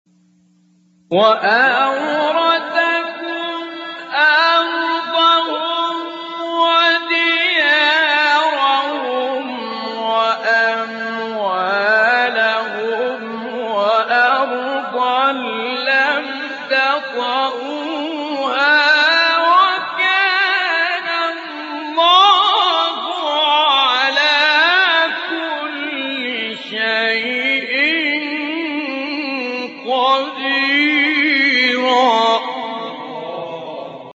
گروه شبکه اجتماعی: مقاطع صوتی از سوره احزاب با صوت سید متولی عبدالعال ارائه می‌شود.
به گزارش خبرگزاری بین المللی قرآن (ایکنا) فرازهایی صوتی از سوره مبارک احزاب با صدای سید متولی عبدالعال، قاری به نام مصری در کانال تلگرامی تلاوت ناب منتشر شده است، در زیر ارائه می‌شود.